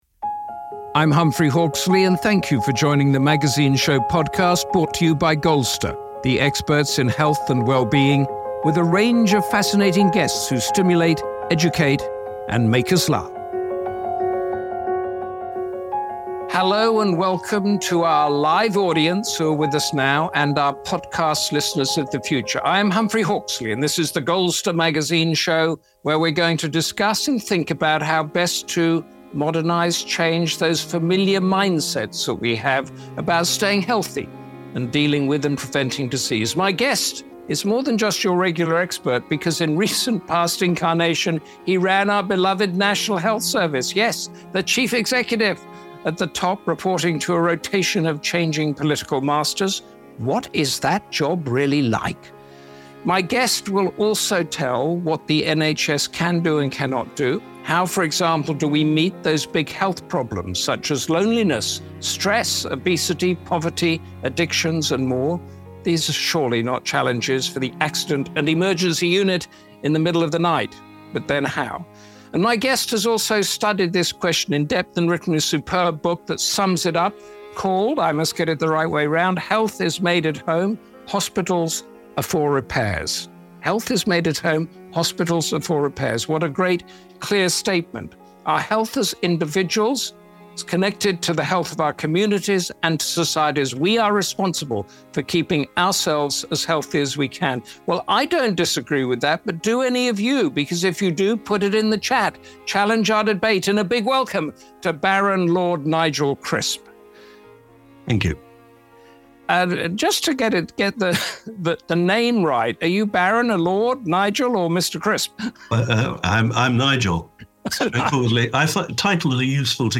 On the Goldster Conversations Podcast you hear captivating interviews with best-selling authors, intrepid explorers, former spies and international sports people.